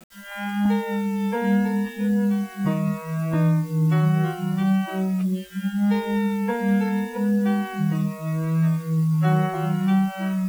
violin.wav